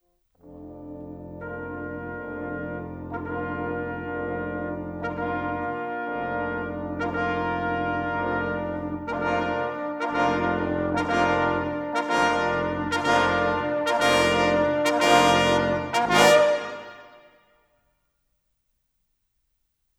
Cinematic 27 Horns 06.wav